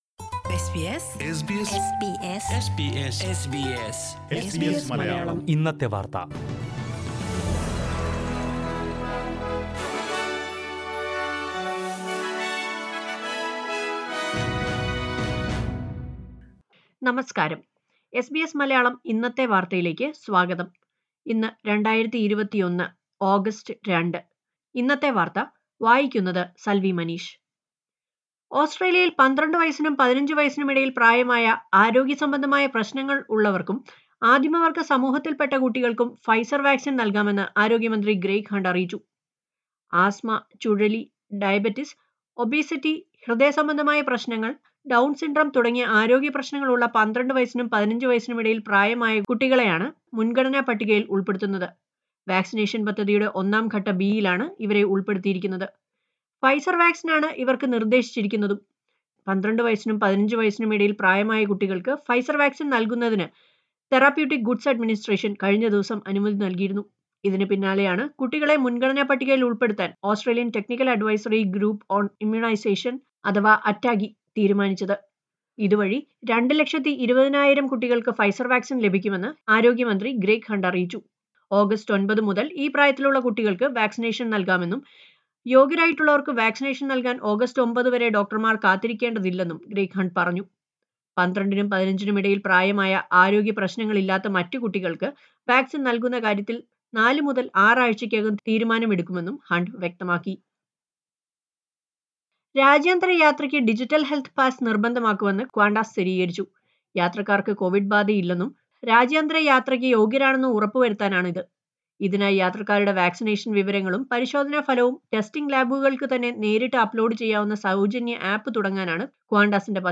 SBS Malayalam Today's News